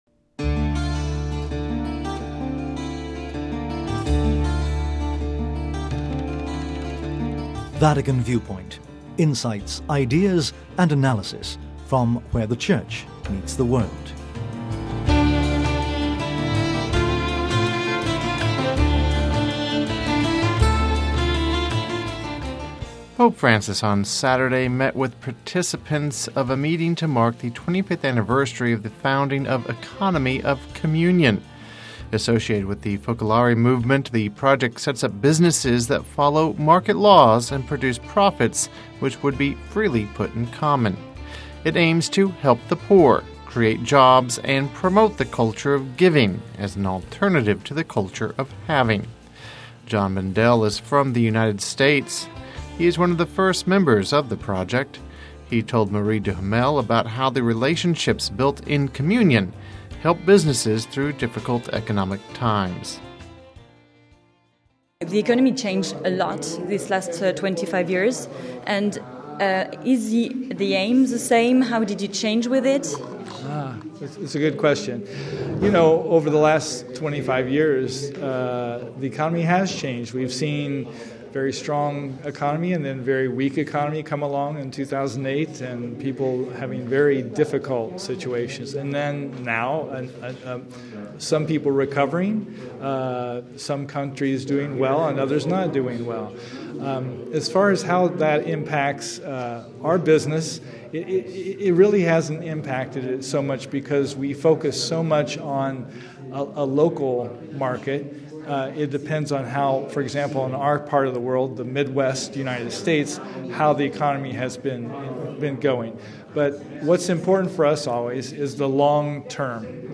Listen to their interview with Vatican Radio: